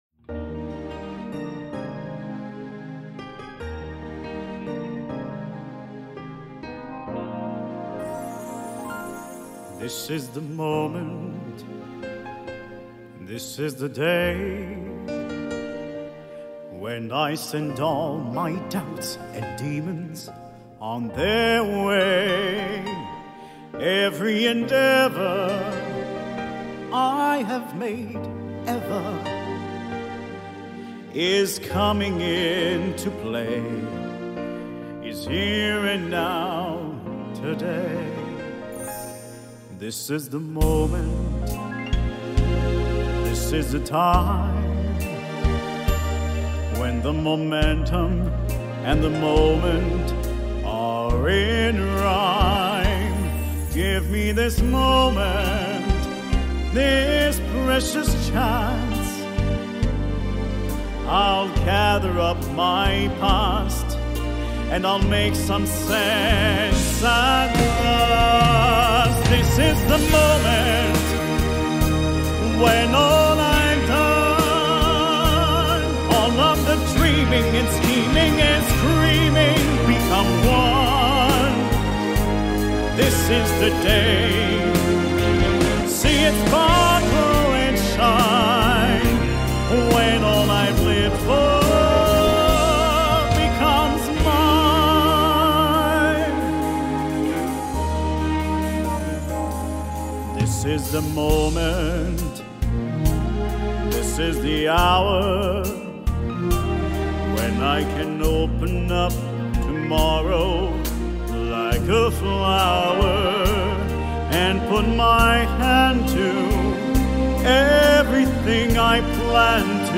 Démo Comédie Musicale